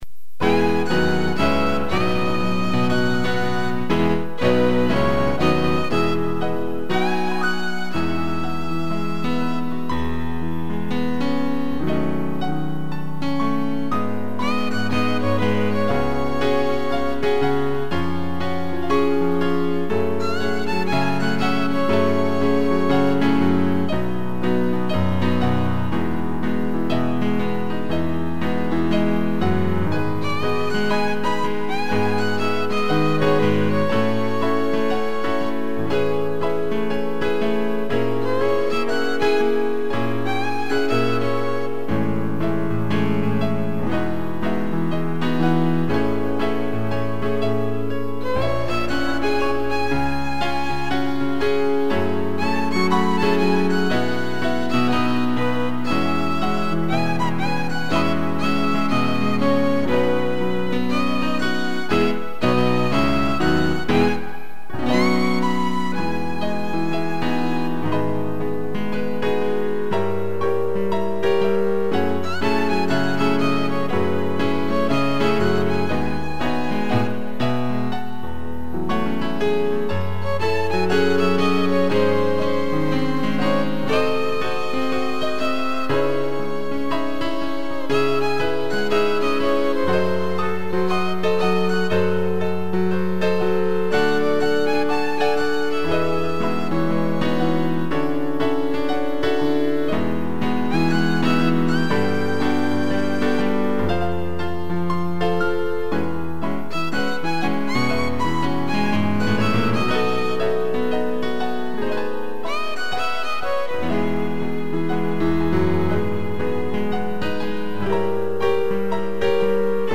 2 pianos e violino
(instrumental)